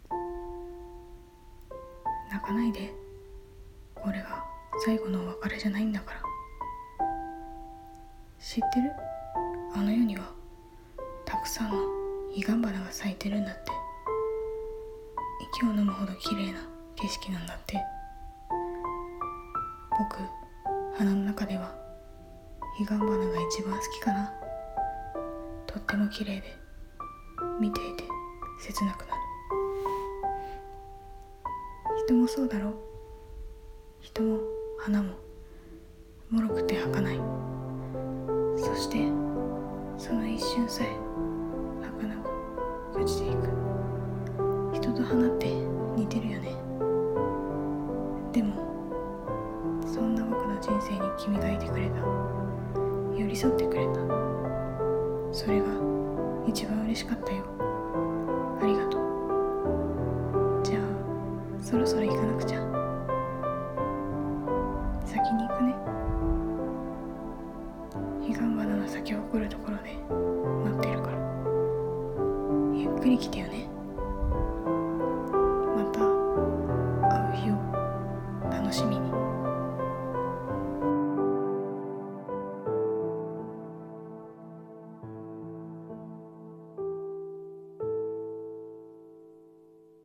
彼岸花【一人朗読台本